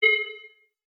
Holographic UI Sounds 83.wav